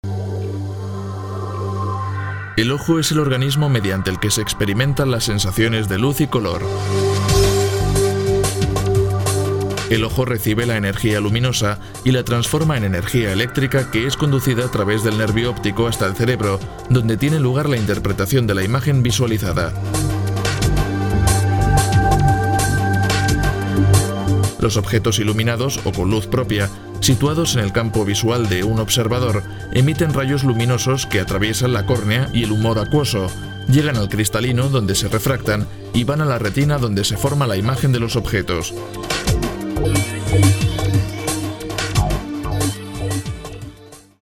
Si está buscando una voz joven, seria, amable y cálida para su proyecto de locución, yo puedo ofrecerle un servicio de calidad, rápido y económico.
Sprechprobe: Werbung (Muttersprache):
Castilian accent (native voice talent from Spain, living in Madrid), ideal if you are looking for an european spanish accent or if your target market is Spain. Warm, deep and sensual for commercial and promos; warm, deep and serious for institutional promos, presentations, etc. Younger voice for other kind of projects.